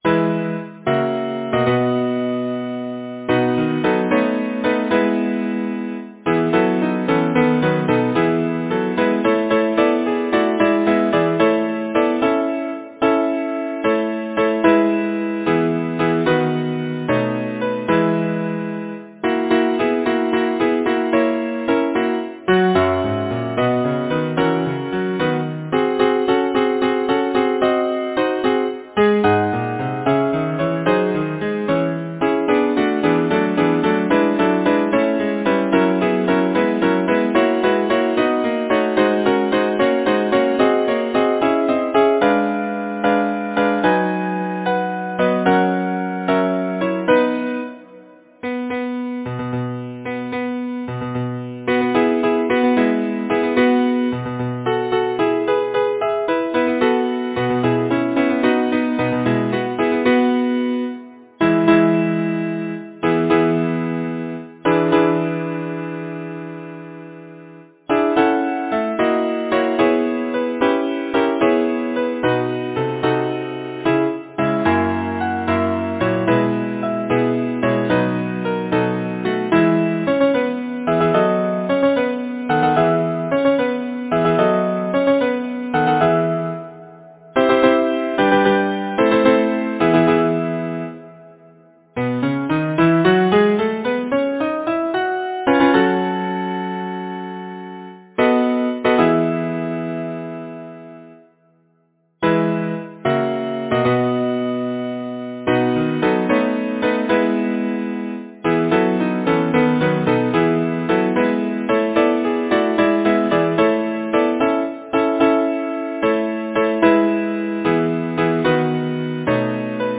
Title: Rise! Sleep no more! Composer: Julius Benedict Lyricist: Bryan Procter Number of voices: 4vv Voicing: SATB Genre: Secular, Partsong
Language: English Instruments: A cappella
First published: 1857 2nd published: ca. 1875 Brainard's Sons Description: Hunting Song